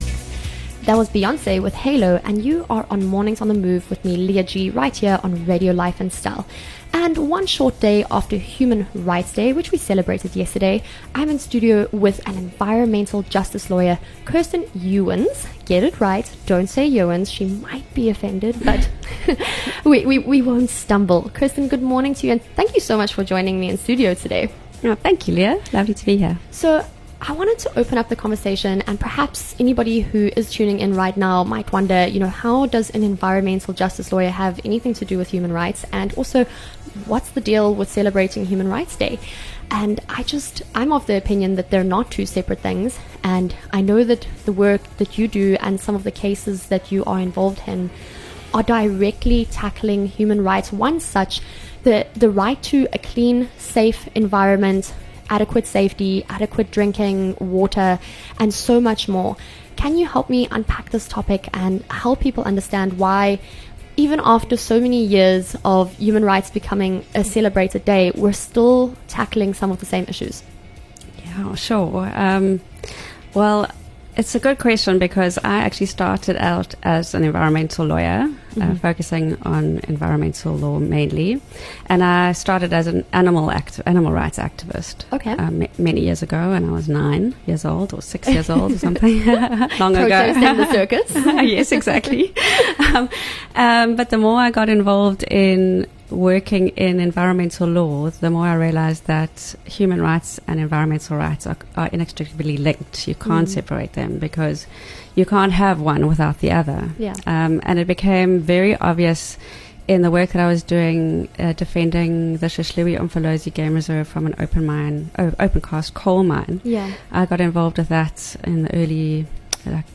23 Mar In conversation with Environmental Justice Lawyer